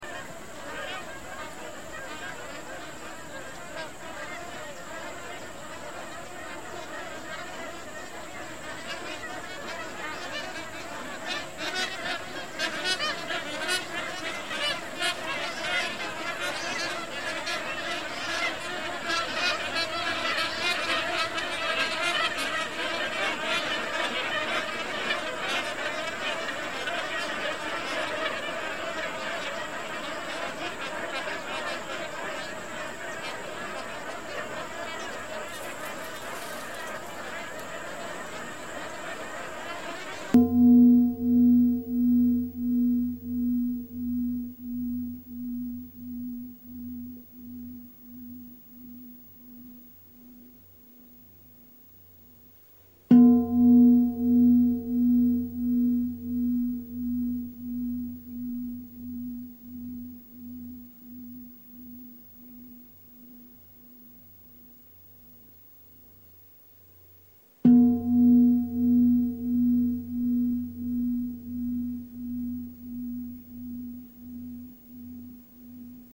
one riverbowl listening